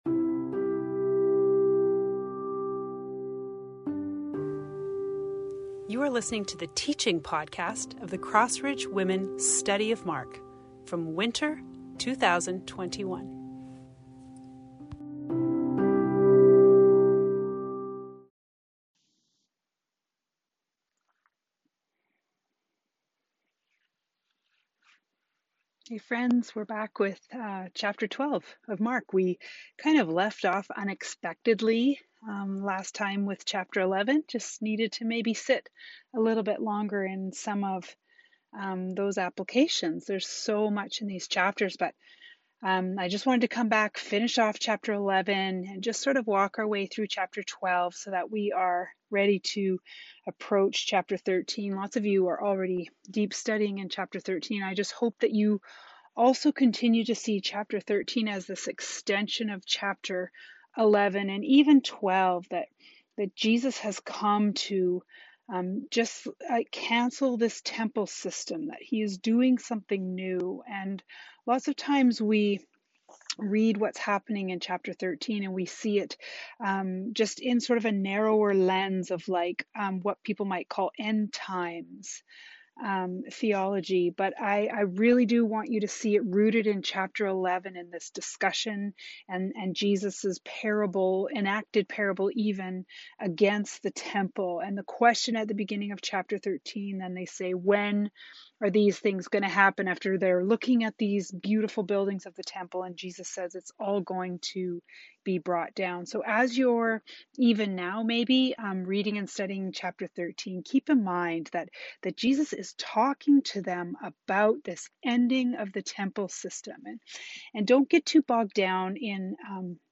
Teaching from our study of Mark 12.